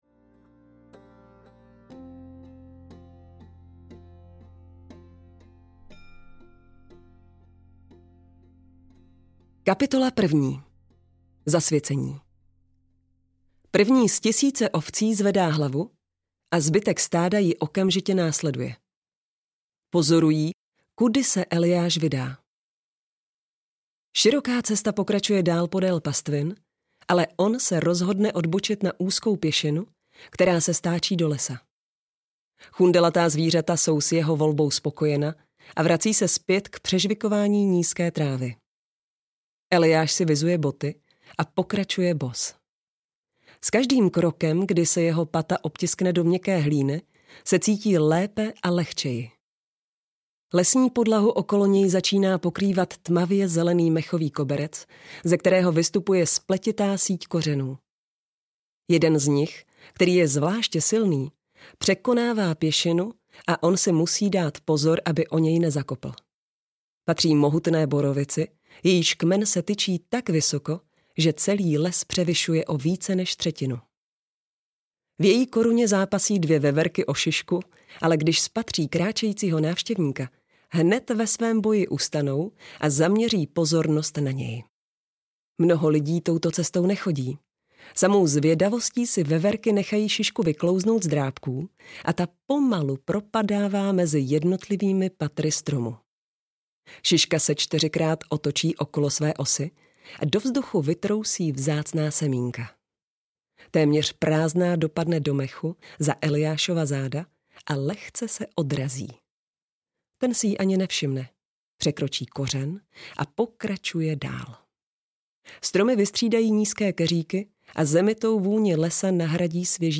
Karlaz: Cesta člověka audiokniha
Ukázka z knihy